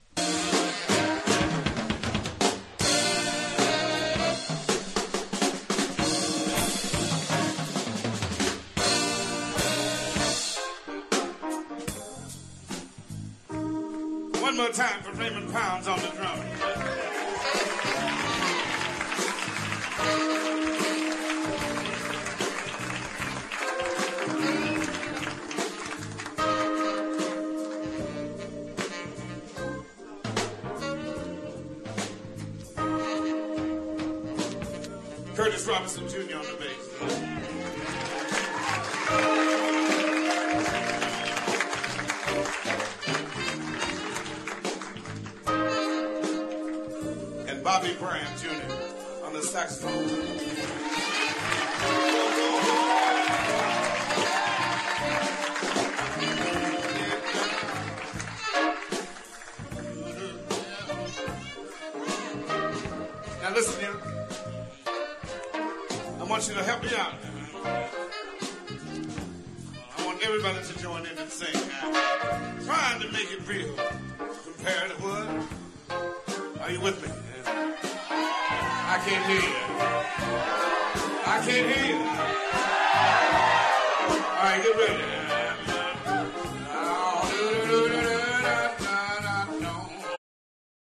ジャズピアニストでありながら自ら弾きながら歌うスタイル。A1では観客の合唱も入るソウルフル＆ピースフルなライブ盤。